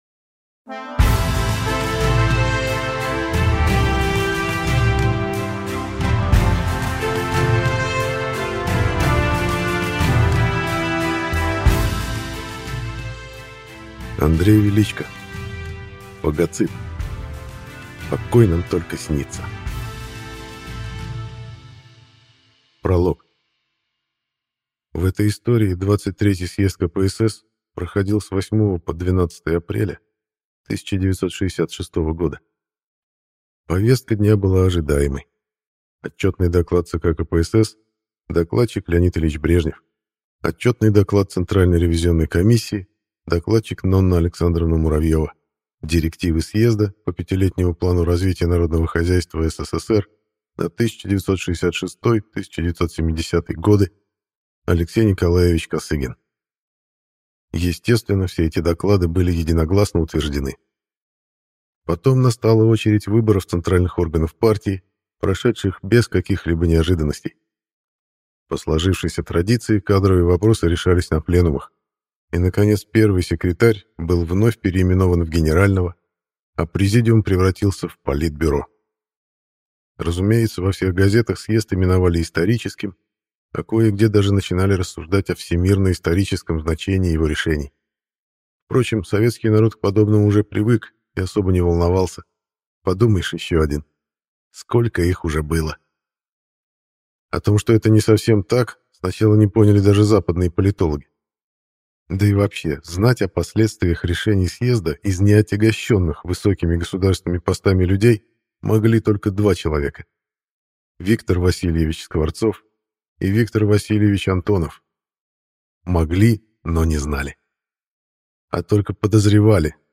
Прослушать фрагмент аудиокниги Фагоцит.